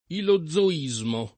ilozoismo [ ilo zz o &@ mo ]